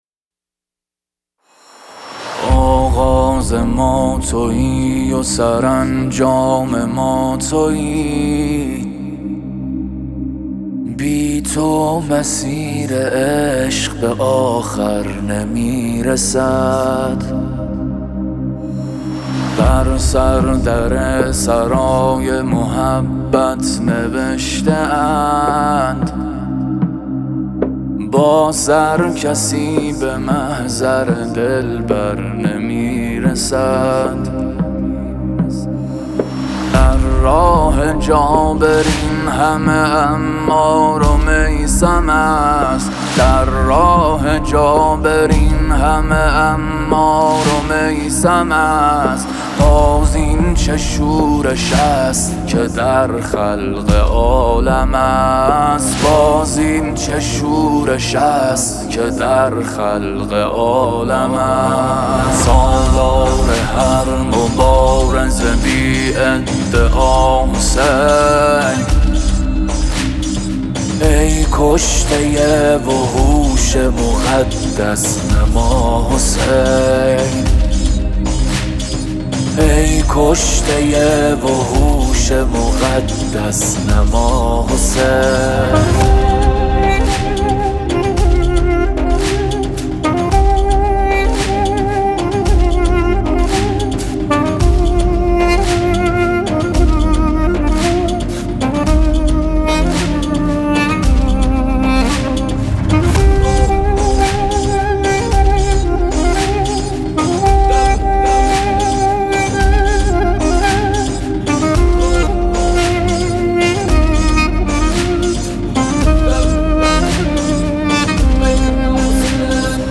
به مناسبت اربعین حسینی